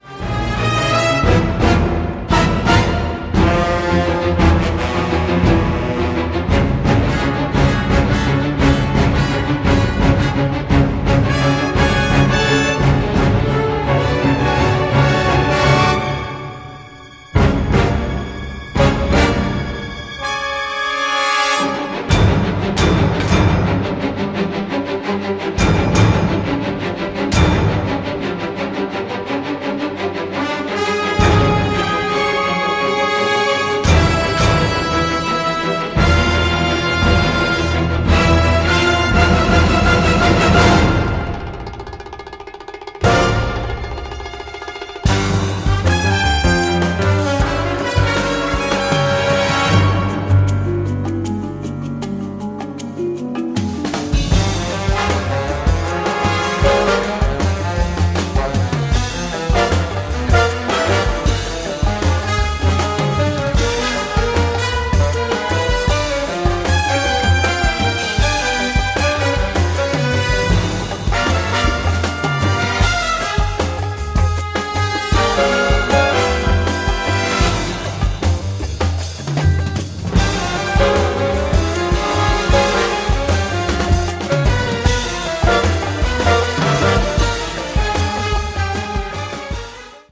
propulsive orchestral score
drenched in delightful old school film-noir vibes